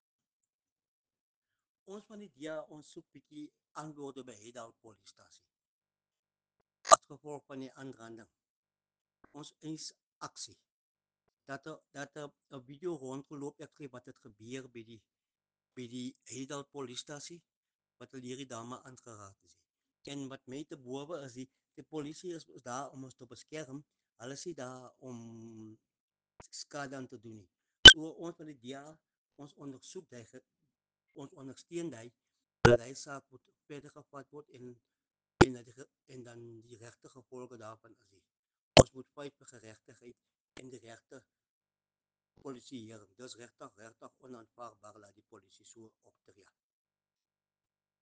Afrikaans soundbites by Cllr Attie Terblanche and